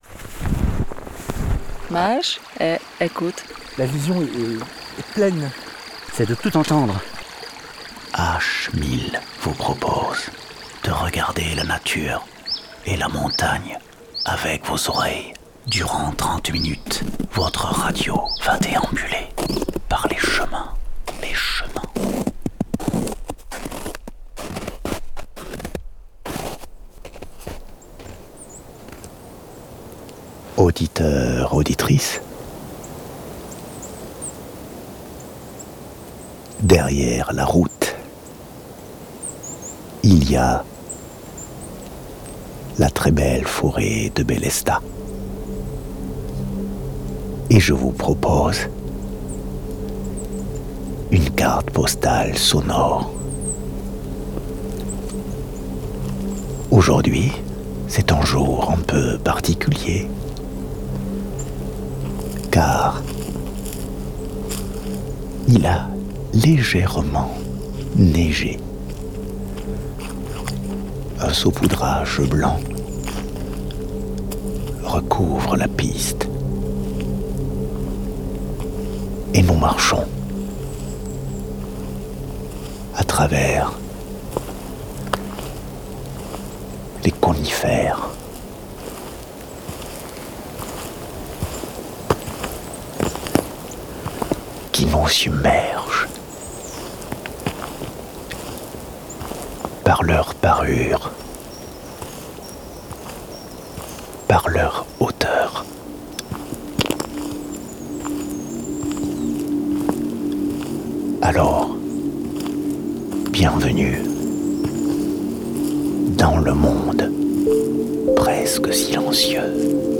Ambiance pluvieuse